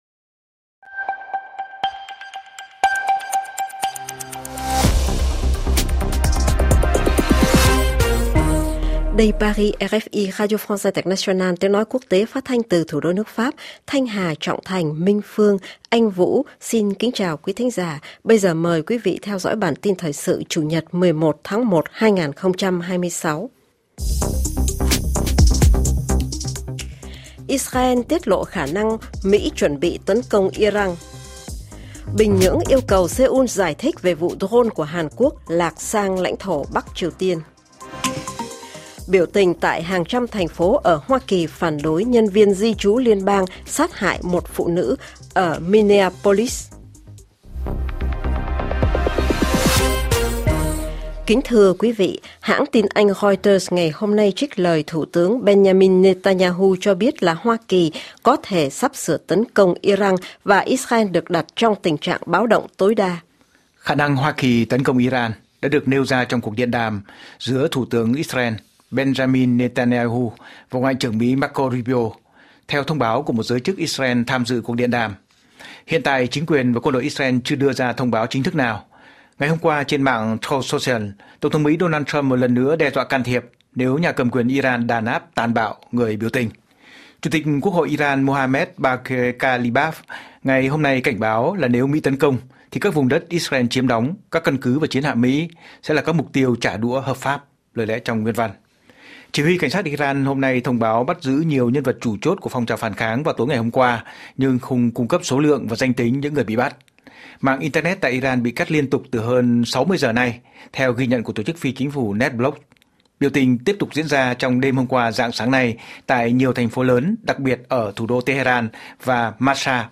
CHƯƠNG TRÌNH 60 PHÚT - RFI Studio phát thanh RFI.